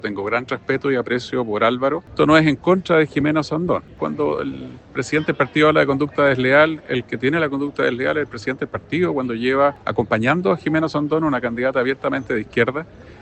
En conversación con La Radio, el alcalde de Santiago salió al paso de los cuestionamientos y defendió su decisión.